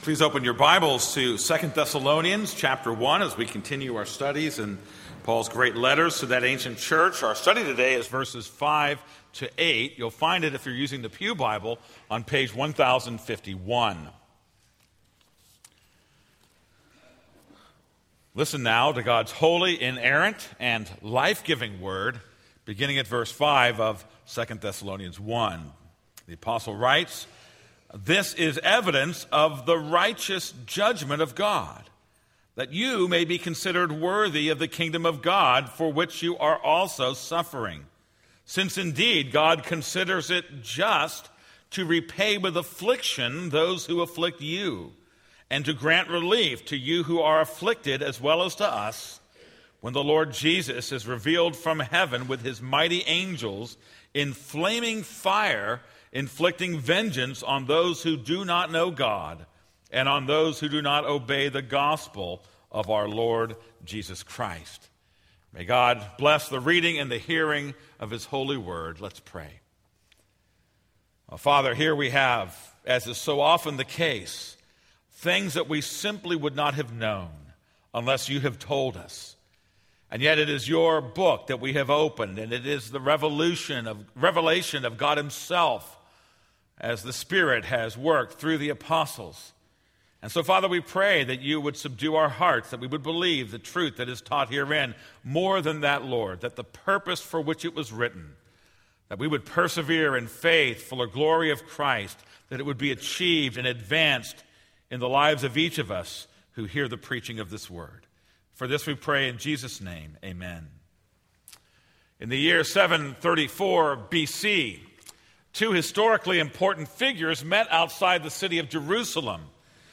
This is a sermon on 2 Thessalonians 1:5-8.